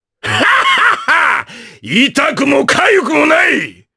Ricardo-Vox_Skill4_jp.wav